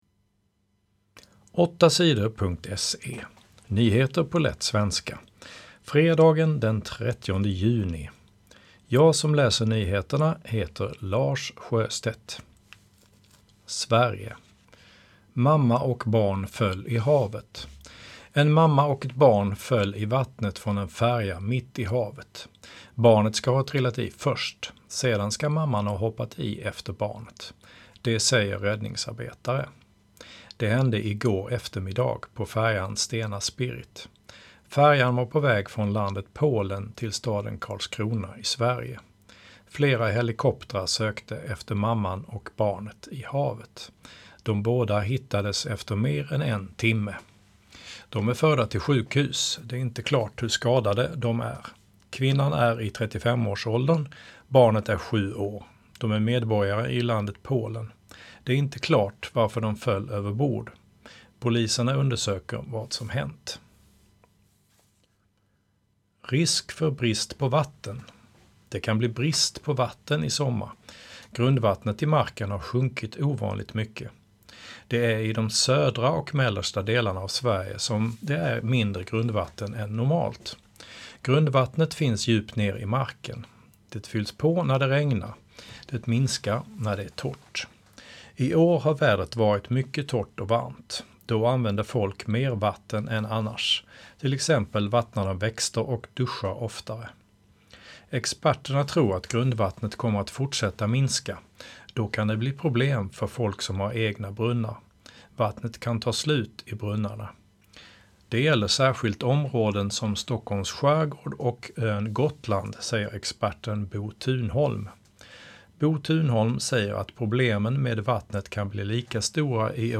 Nyheter på lätt svenska 30 juni